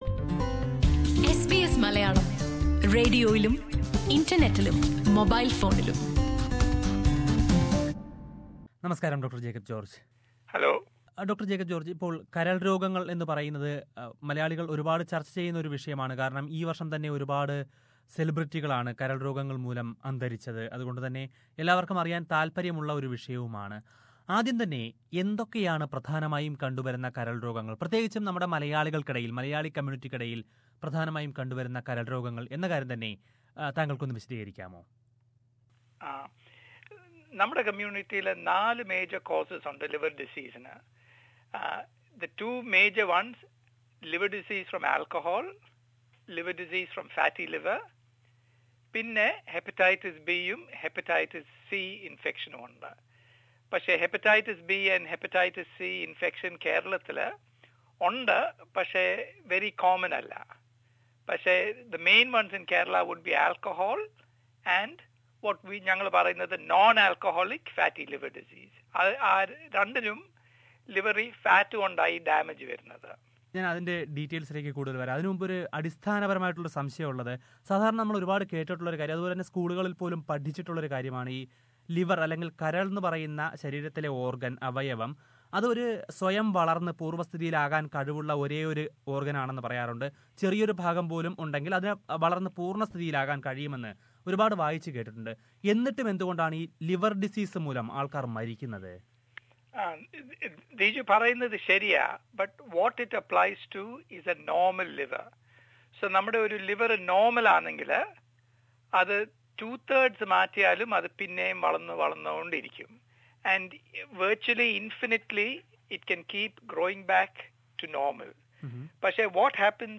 ഈ സാഹചര്യത്തിൽ ഒരു കരൾ രോഗ ചികിത്സാ വിദഗ്ധനുമായി സംസാരിക്കുകയാണ് എസ് ബി എസ് മലയാളം.